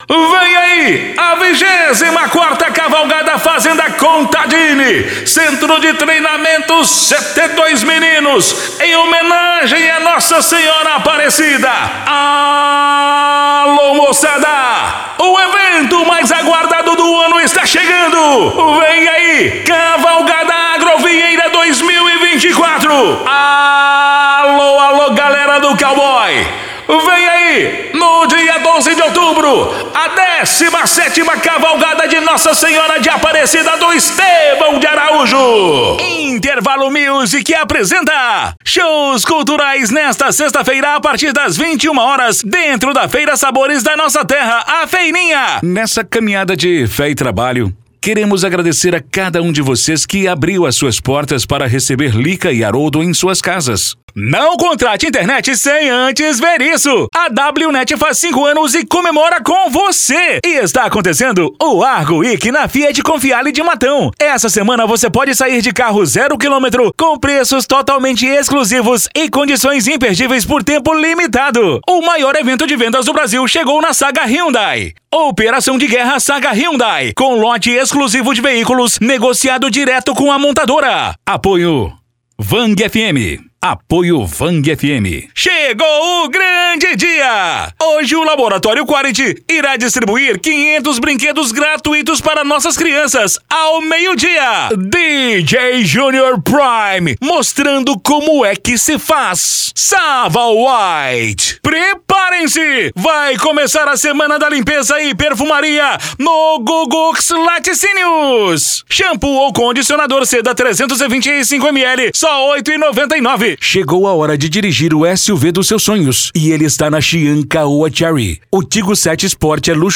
Spot Comercial
Vinhetas
Padrão
Animada
Caricata